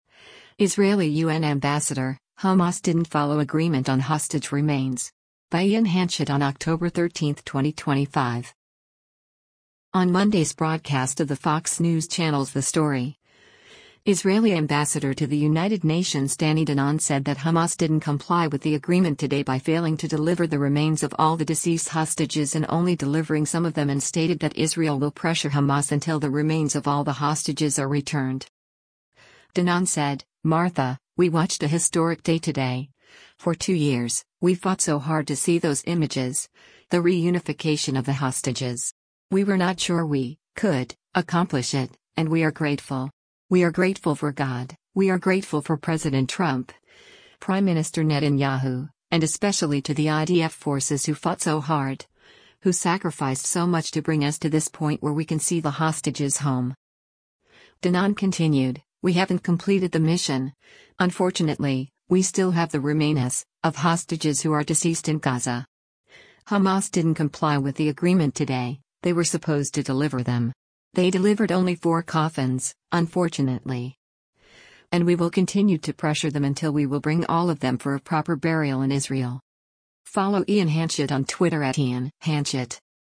On Monday’s broadcast of the Fox News Channel’s “The Story,” Israeli Ambassador to the United Nations Danny Danon said that “Hamas didn’t comply with the agreement today” by failing to deliver the remains of all the deceased hostages and only delivering some of them and stated that Israel will pressure Hamas until the remains of all the hostages are returned.